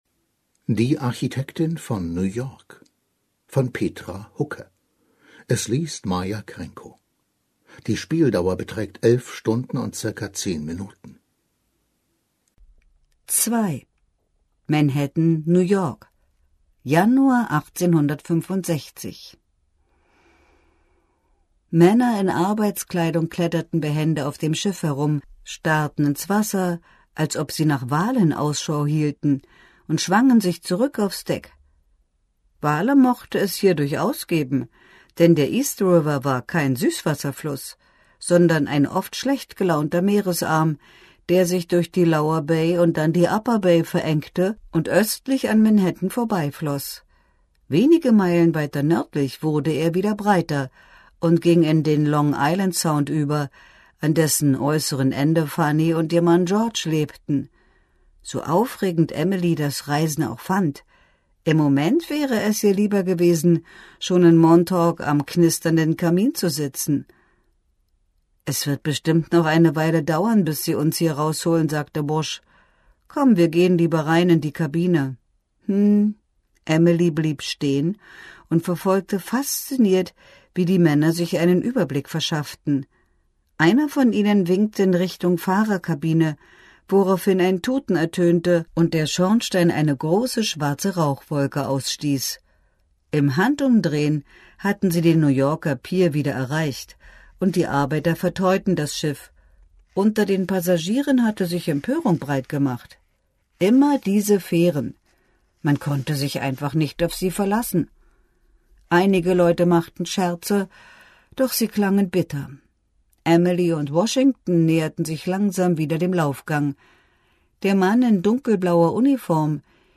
liest die historischen Roman einer grandiosen Frau für Sie: